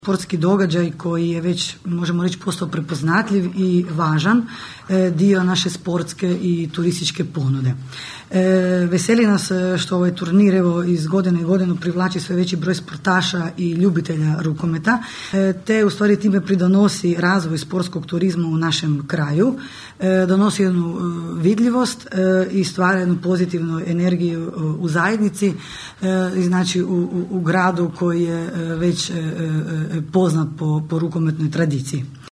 Na današnjoj konferenciji za novinare predstavljen je četvrti međunarodni rukometni turnir Labin Handball Cup, koji će se održati 30. i 31. siječnja iduće godine.